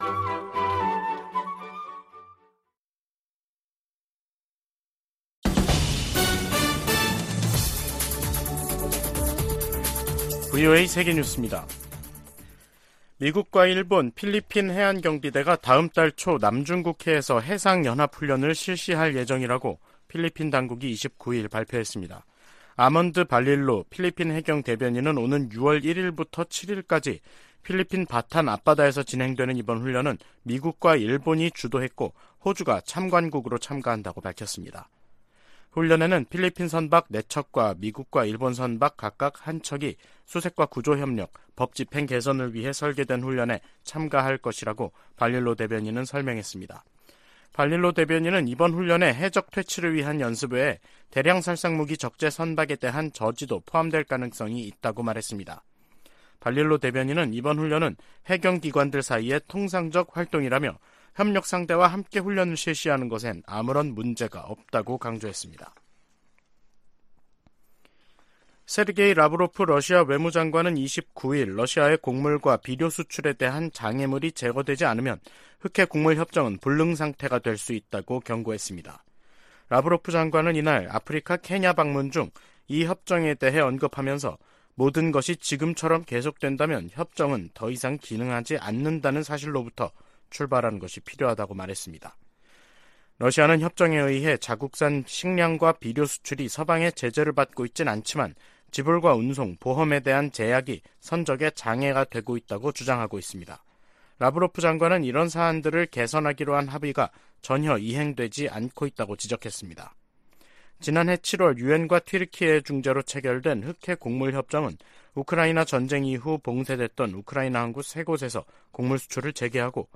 VOA 한국어 간판 뉴스 프로그램 '뉴스 투데이', 2023년 5월 29일 3부 방송입니다. 북한이 군사정찰위성 1호기 발사 계획을 공개했습니다.